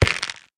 25w18a / assets / minecraft / sounds / block / stem / step5.ogg
step5.ogg